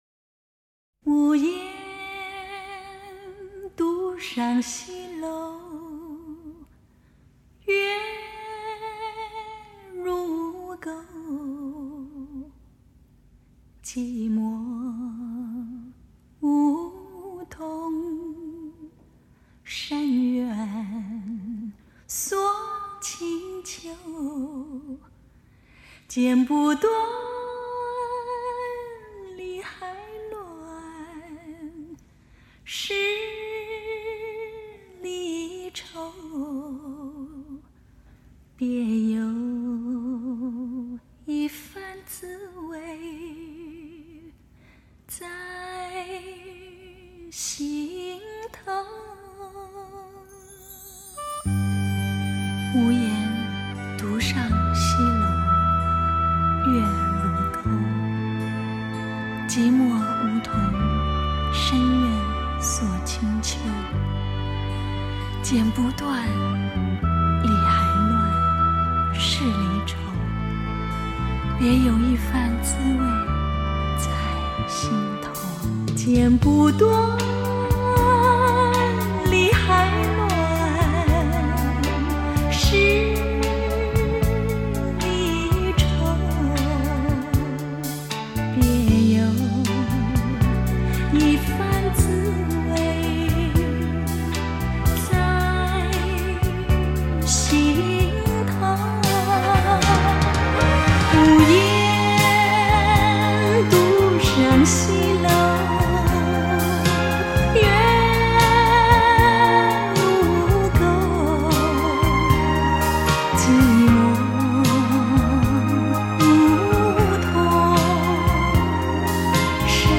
原装母带采用JVC超解析技术处理
整张碟以人声为主，配乐的音量较低，乐队的位置也明显靠后
人声如水晶般纯净，音色温暖清丽
民乐和管弦伴奏音质平滑有光泽